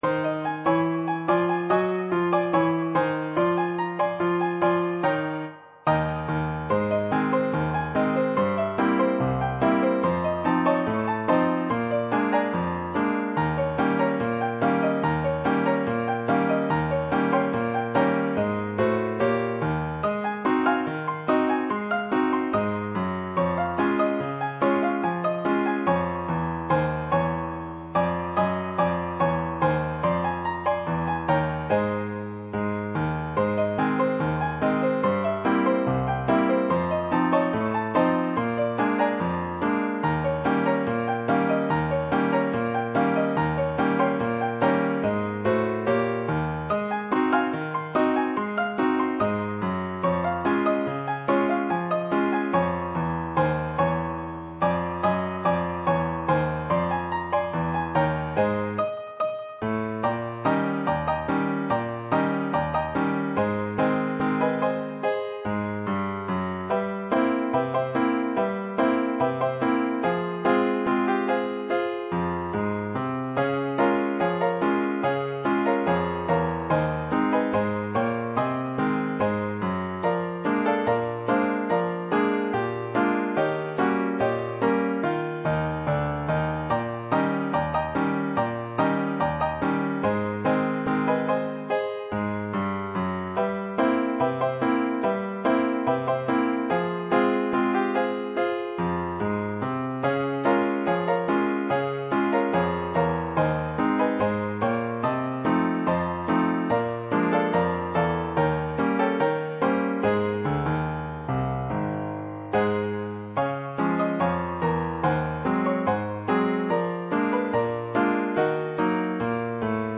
This is not a performance version, but is simply a file which lilypond produces when processing the source.
An mpeg (.mp3) file produced from the midi file.